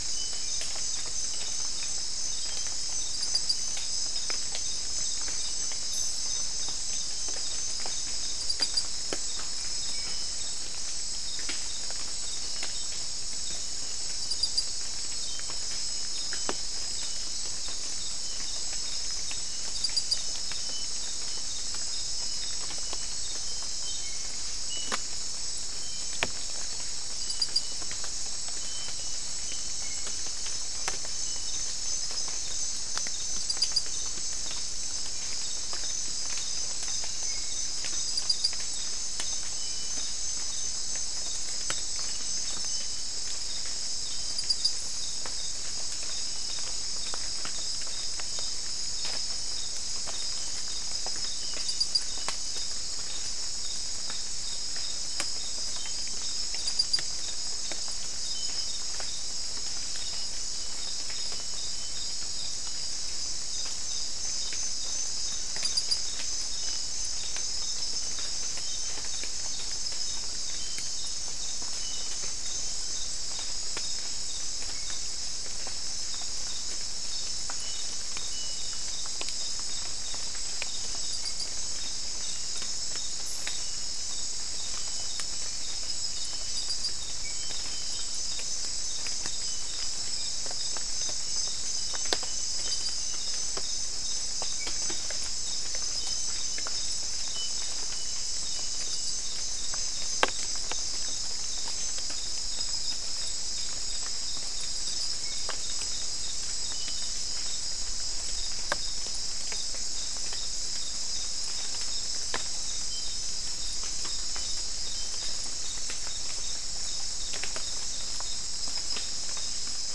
Soundscape Recording Location: South America: Guyana: Kabocalli: 4
Recorder: SM3